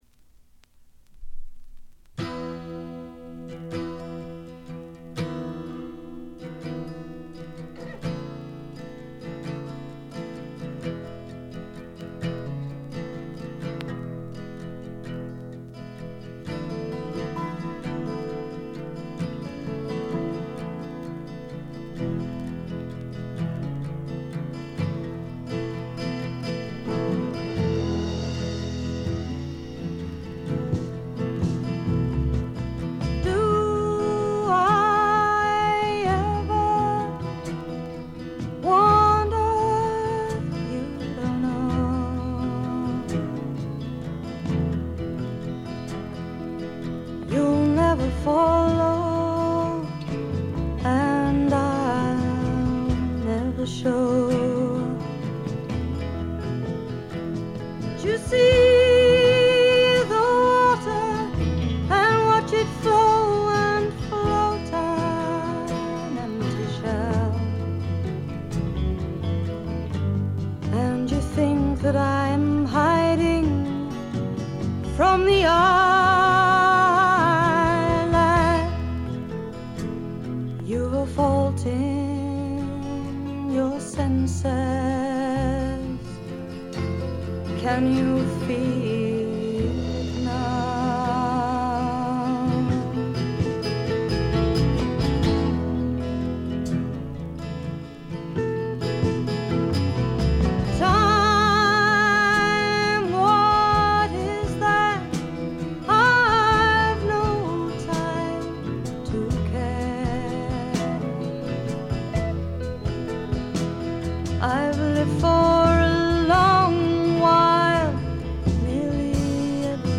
軽微なバックグラウンドノイズ、チリプチ、散発的な軽いプツ音が少し。
英国フォークロックの基本中の基本！！
試聴曲は現品からの取り込み音源です。